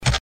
manmove.wav